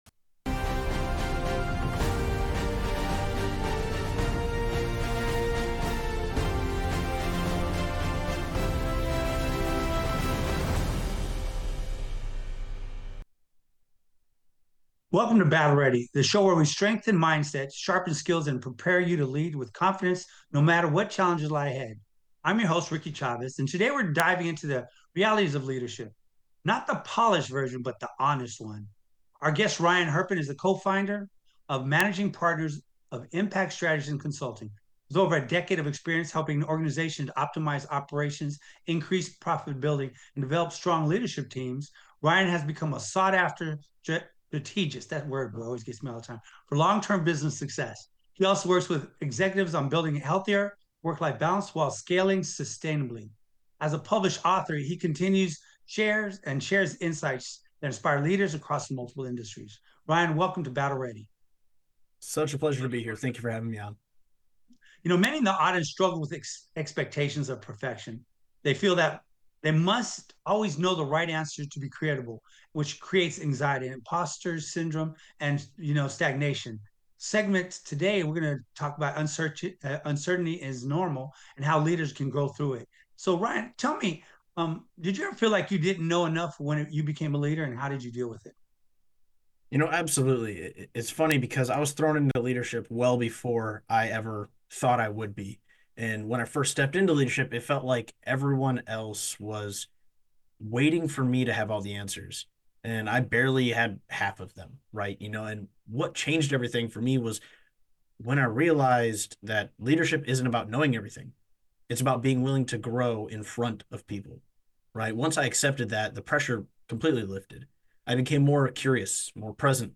for an honest and grounded conversation about leadership, uncertainty, and the pressure many leaders feel to appear perfect.